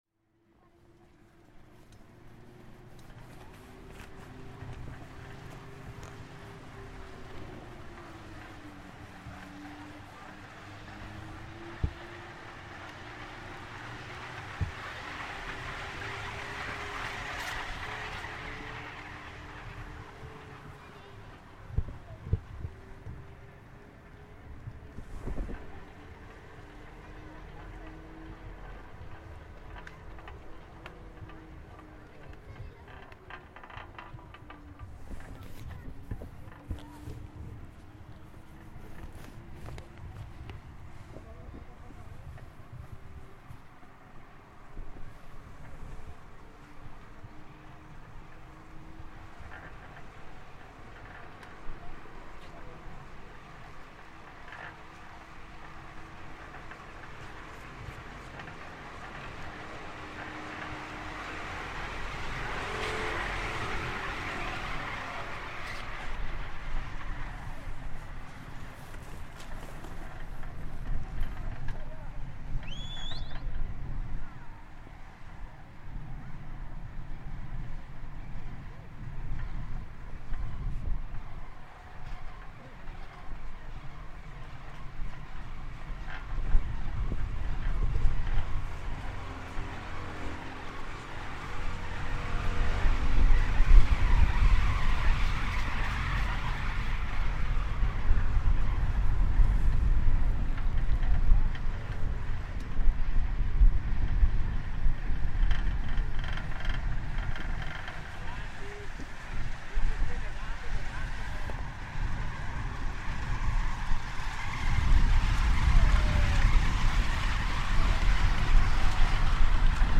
The sounds from on board a simple two-seater ski lift transporting skiers up the mountain in Passo Rolle, San Martino di Castrozza, Italy.